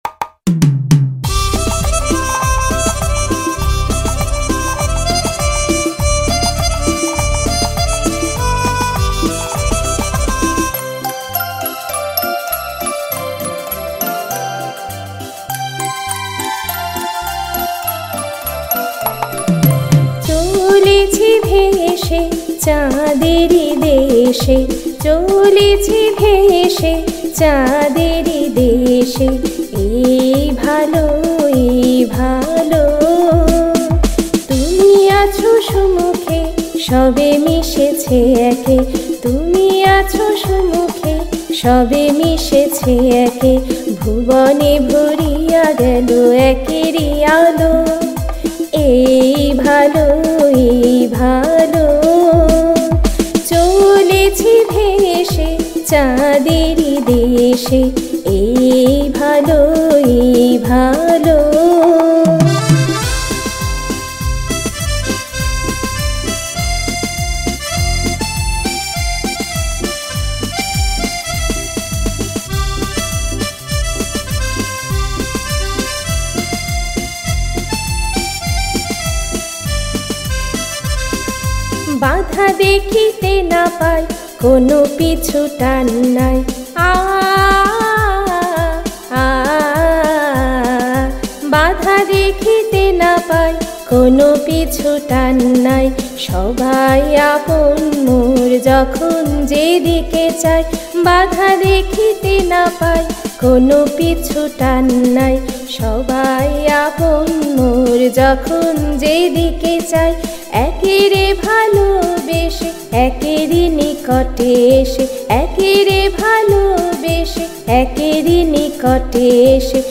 Music Kaharva